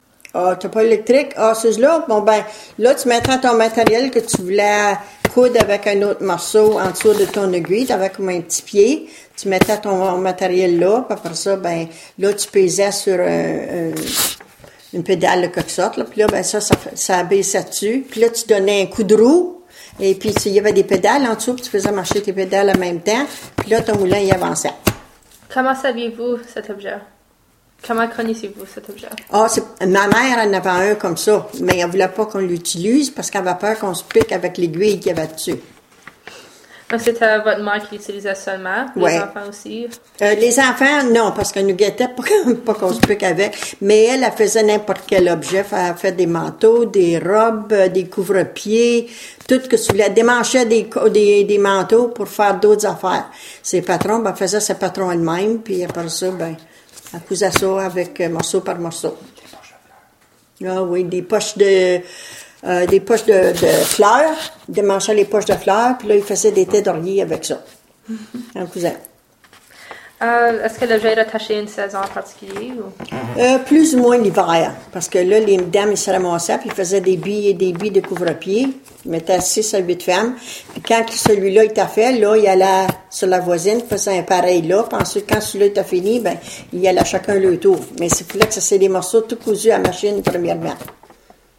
Entrevue : La machine à coudre / Interview: The Sewing Machine